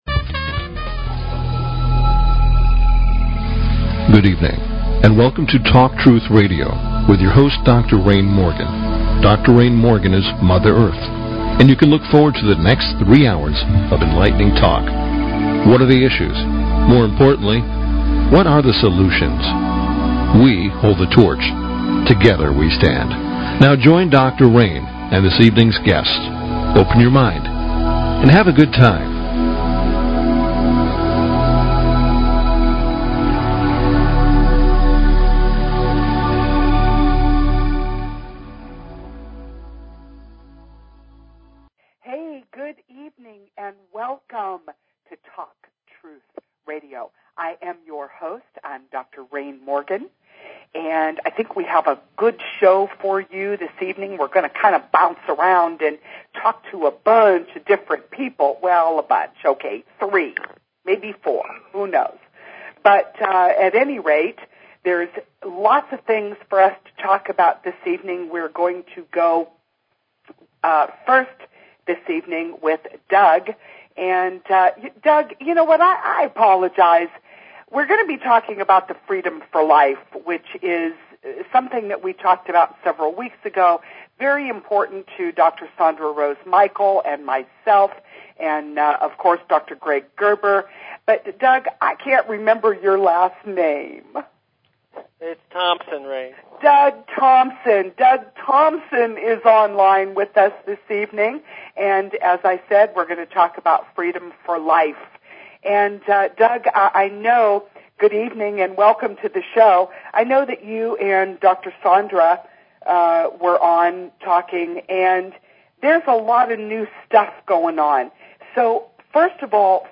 Talk Show Episode, Audio Podcast, Talk_Truth_Radio and Courtesy of BBS Radio on , show guests , about , categorized as